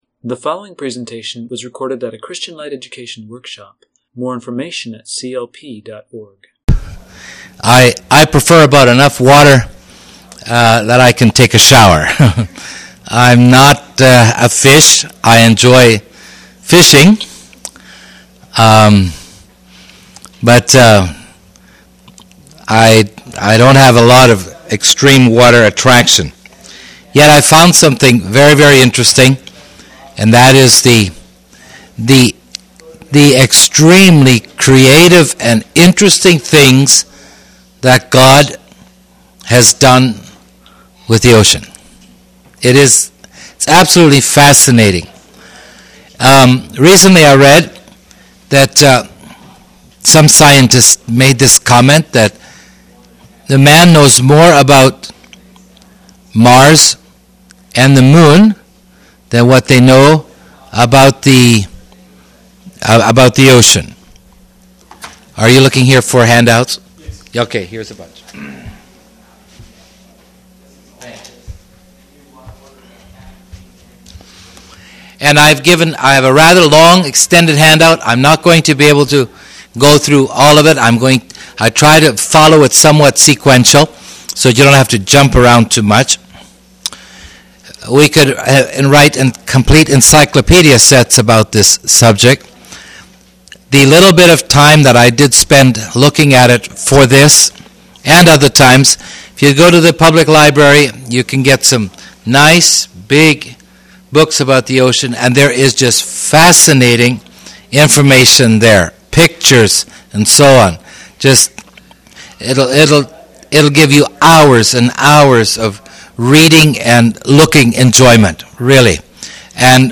Home » Lectures » Wonders of the Deep